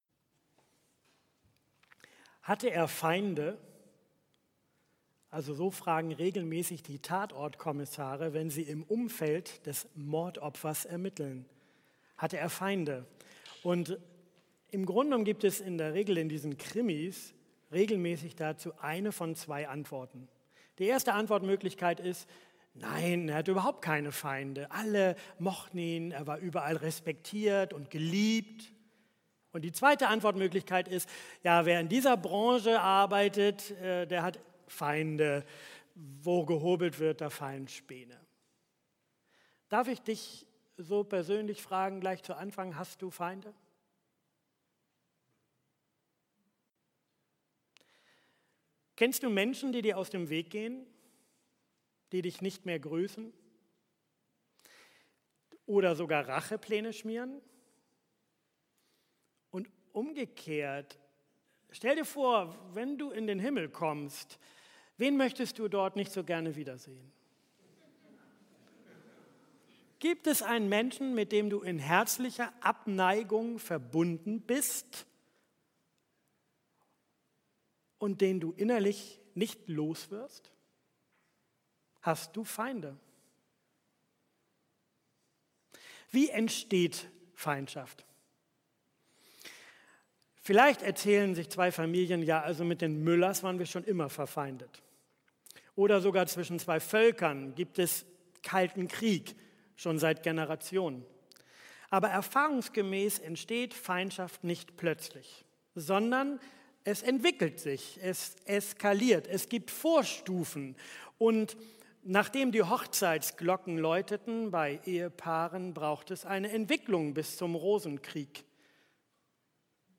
Predigttext: Sprüche 25,21-22; Matthäus 5, 43-48